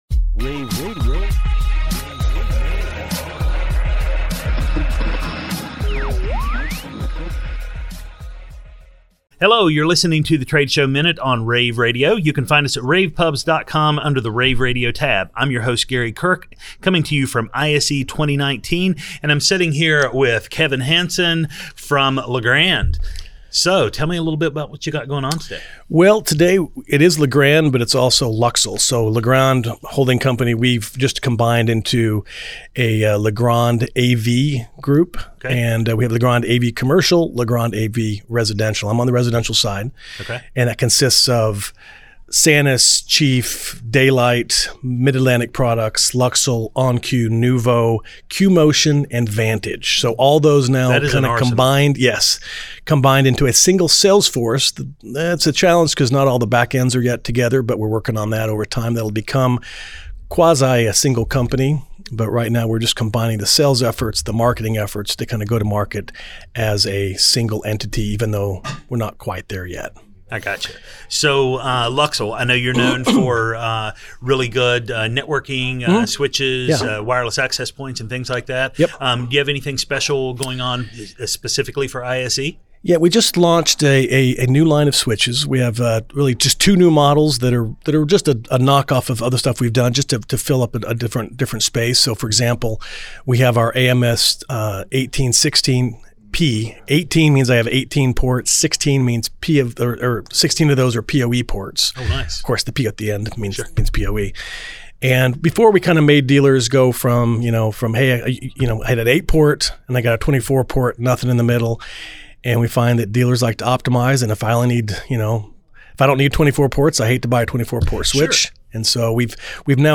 February 5, 2019 - ISE, ISE Radio, Radio, rAVe [PUBS], The Trade Show Minute,